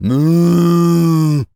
pgs/Assets/Audio/Animal_Impersonations/cow_moo_08.wav at master
cow_moo_08.wav